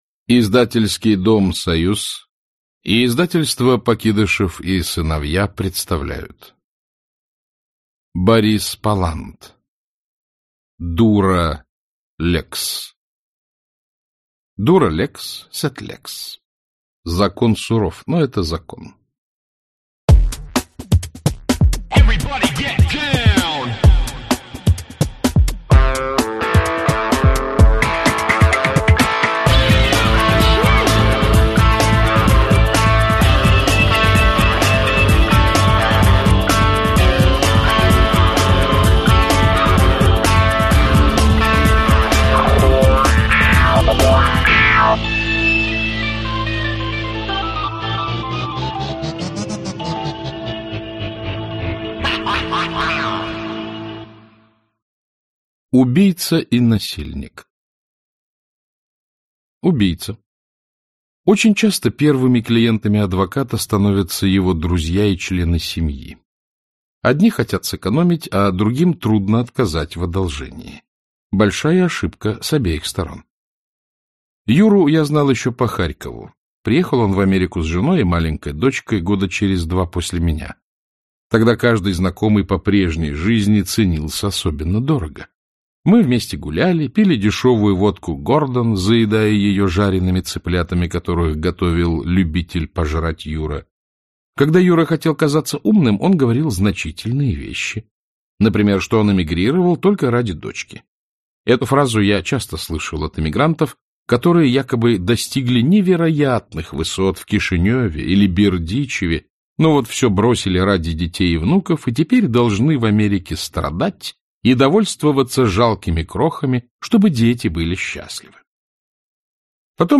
Аудиокнига Дура LEX. Часть 2 | Библиотека аудиокниг